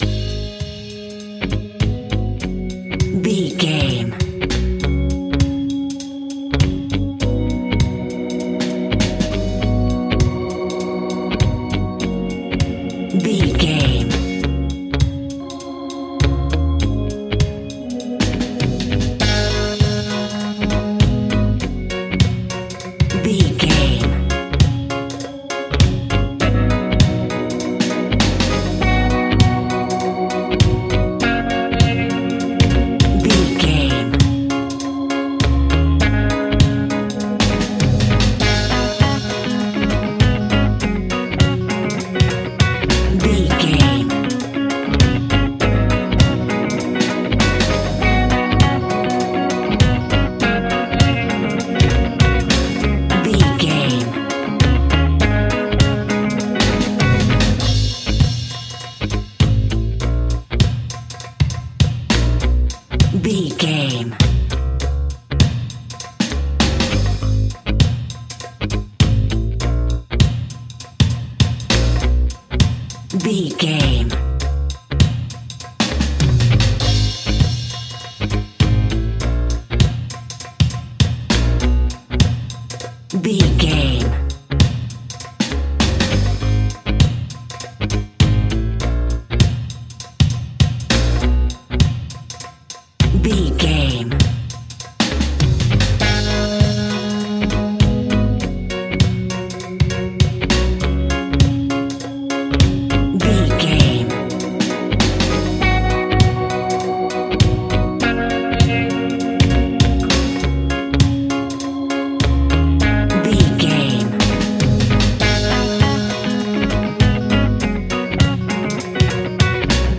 A nice bouncy and upbeat piece of Reggae music.
Aeolian/Minor
G#
Slow
dub
laid back
chilled
off beat
drums
skank guitar
hammond organ
percussion
horns